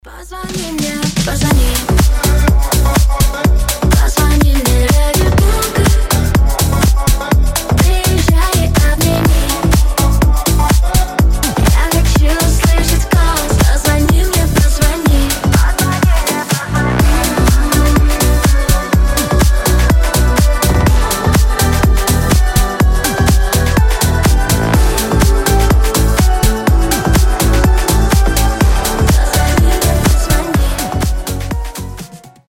• Качество: 320, Stereo
Club House
ремиксы